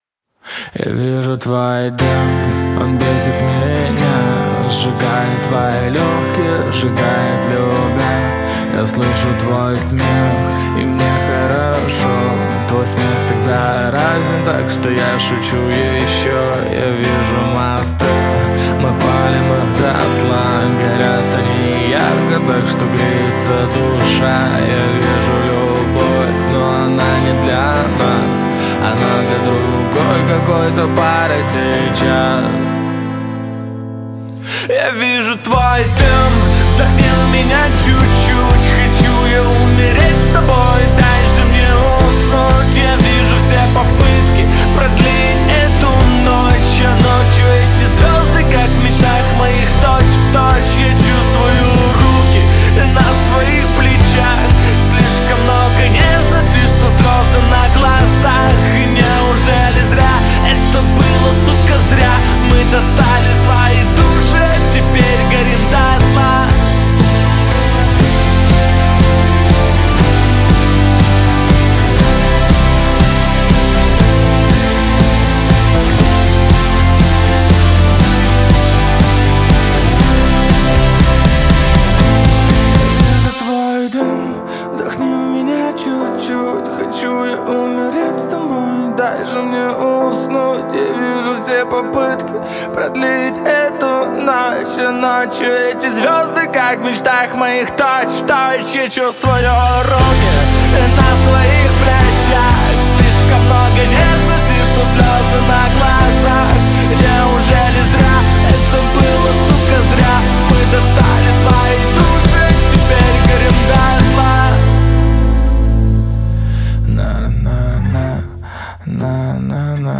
который сочетает в себе элементы поп-рока и инди.